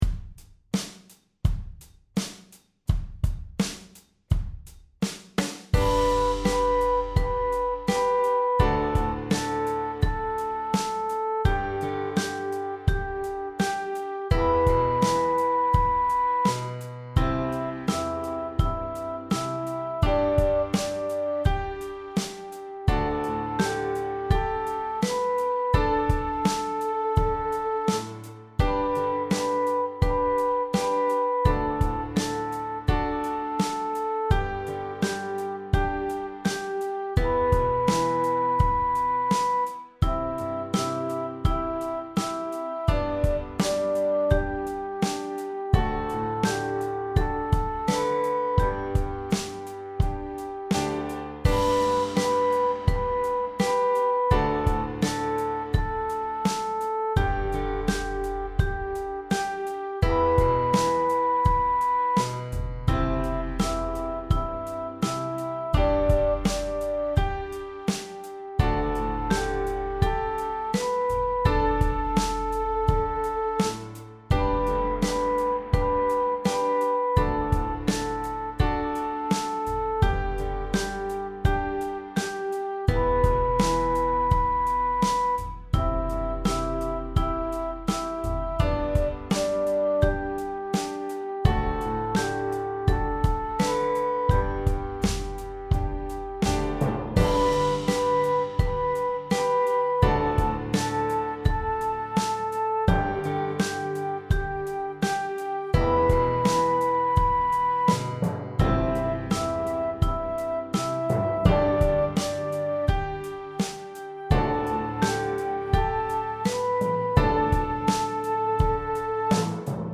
คลิปเสียงประกอบการสอน เรื่อง รีคอร์เดอร์ (1)